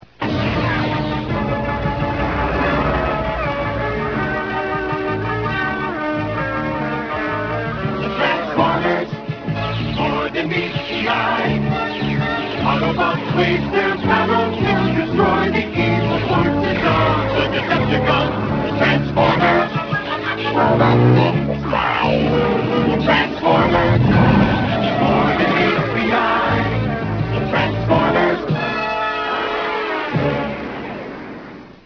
> Click here to listen to the cartoon's original theme song.